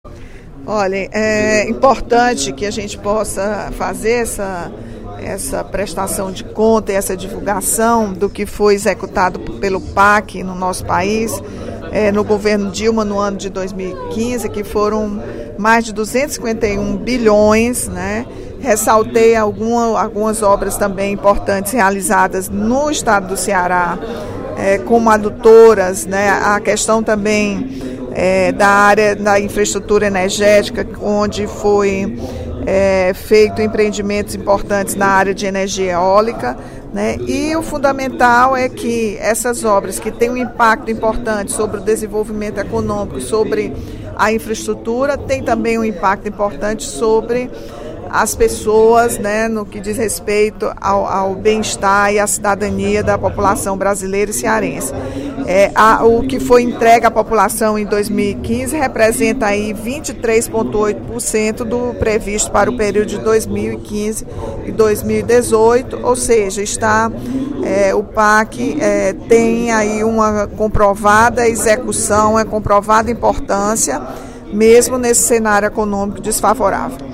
A deputada Rachel Marques (PT) fez pronunciamento nesta quarta-feira (02/03), no primeiro expediente da Assembleia Legislativa, para avaliar a execução do Programa de Aceleração do Crescimento (PAC) em 2015.